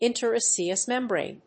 interosseous+membrane.mp3